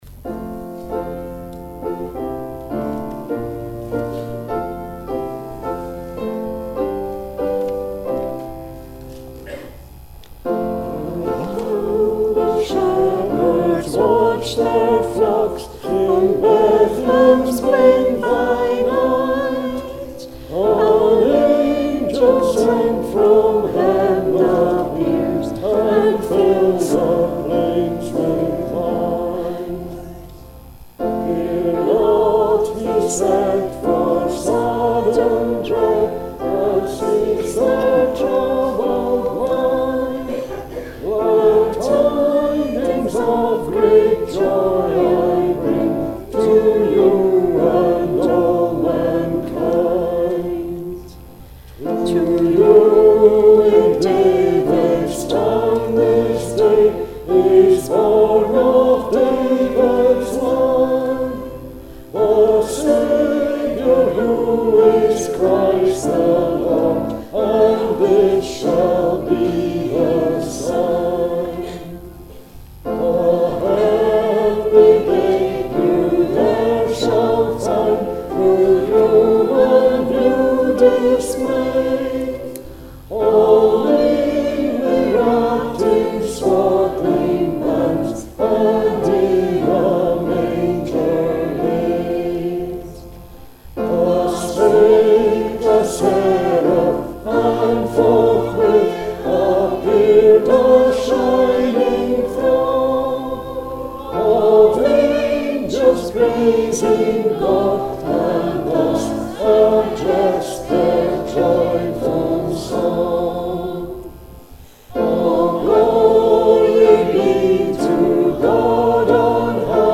Hymn 296 'While humble shepherds'.